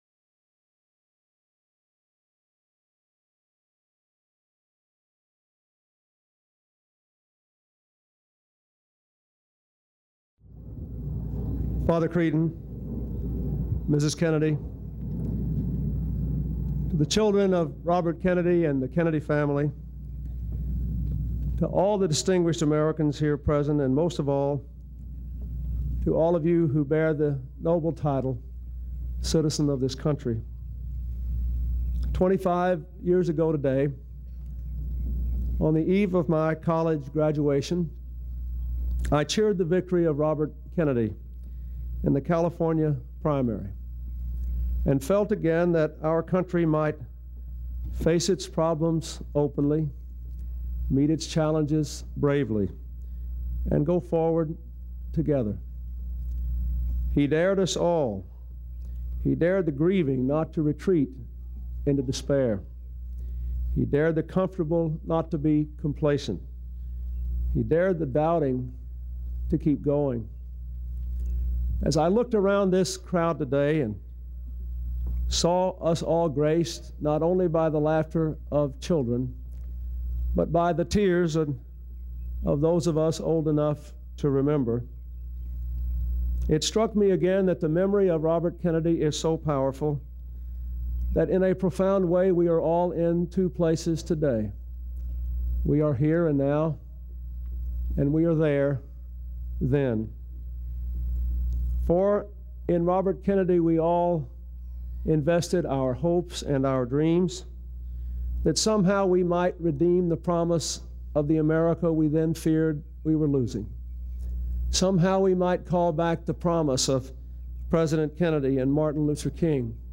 June 6, 1993: Speech at the 25th Anniversary Memorial Mass for Robert F. Kennedy
President Clinton speaks at a memorial mass for Robert F. Kennedy on the 25th anniversary of his assassination.